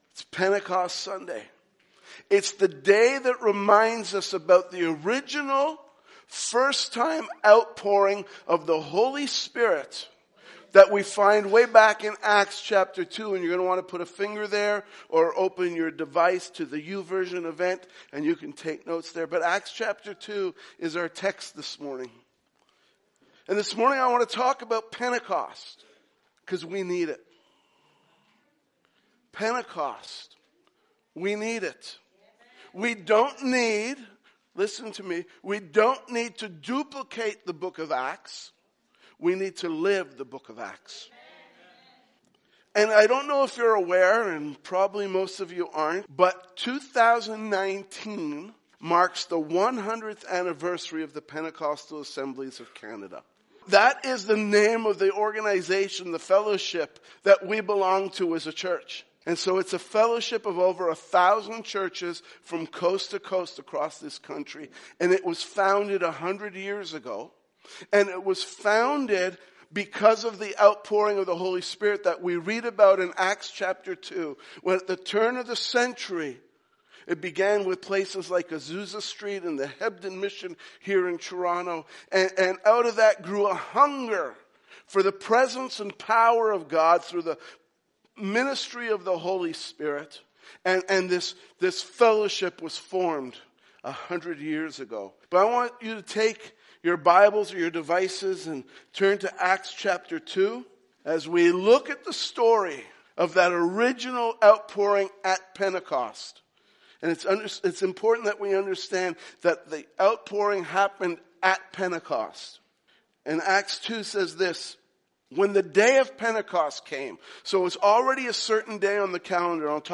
Sermons | Highway Gospel Church